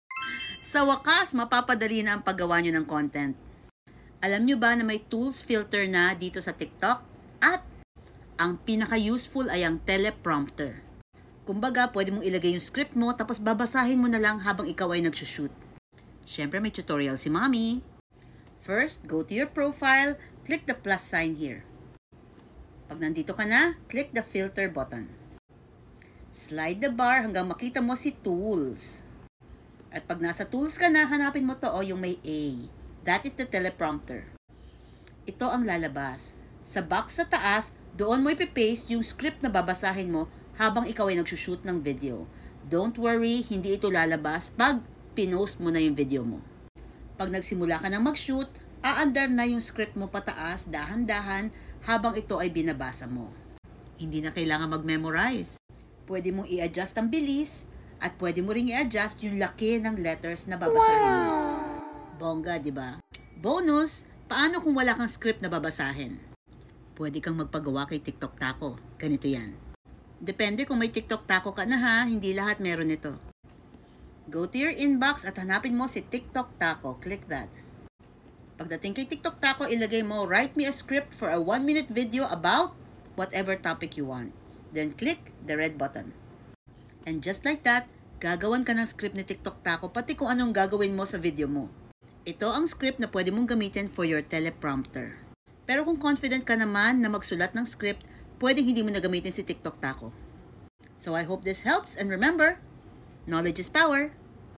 Check out this new tutorial sound effects free download